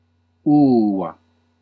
Ääntäminen
France: IPA: [lə.ʀɛ.zɛ̃]